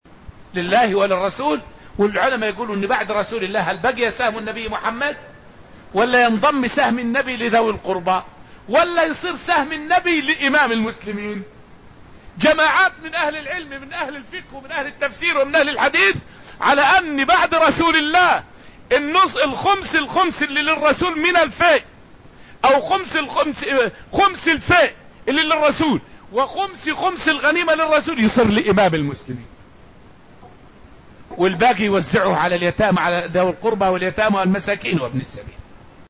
فائدة من الدرس الثالث من دروس تفسير سورة الحشر والتي ألقيت في المسجد النبوي الشريف حول سهم النبي ــ صلى الله عليه وسلم ــ بعد موته.